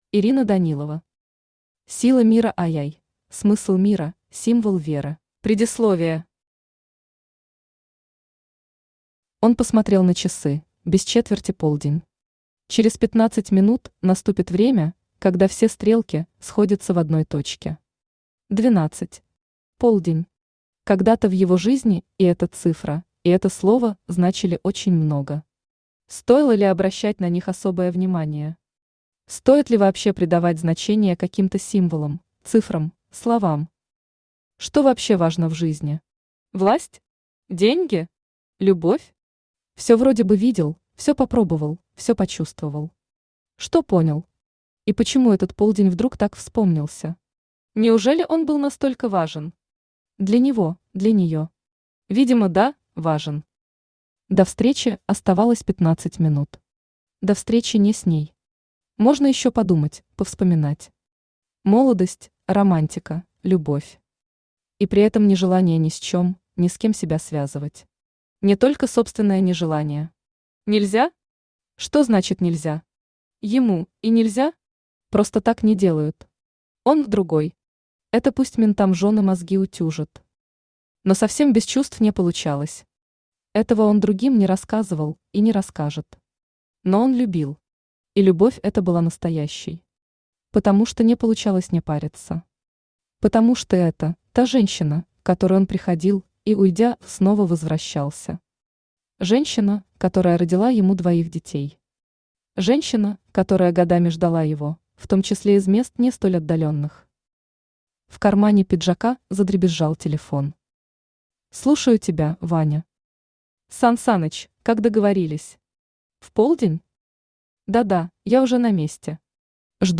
Аудиокнига Сила Мира II. Смысл мира, символ веры | Библиотека аудиокниг
Aудиокнига Сила Мира II. Смысл мира, символ веры Автор Ирина Данилова Читает аудиокнигу Авточтец ЛитРес.